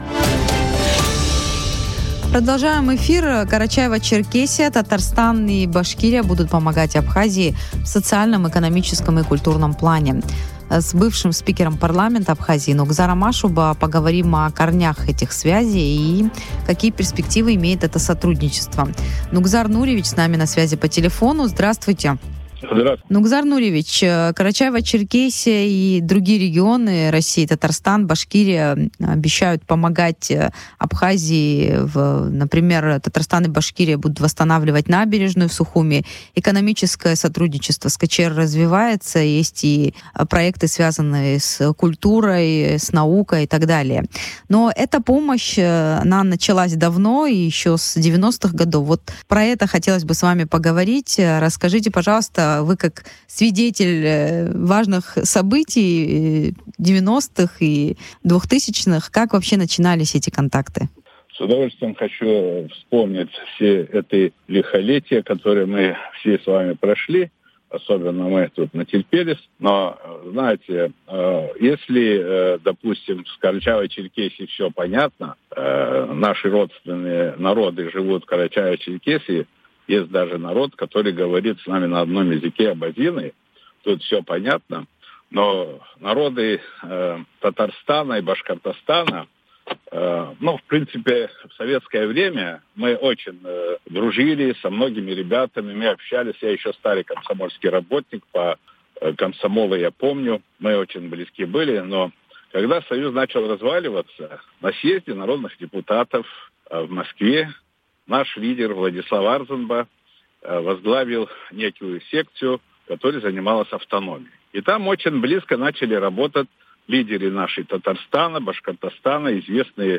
Экс-председатель Парламента РА Нугзар Ашуба в эфире радио Sputnik поделился воспоминаниями, как Абхазия, КЧР, Башкортостан и Татарстан помогали друг другу в разные годы, начиная с тяжелых лет Великой Отечественной войны.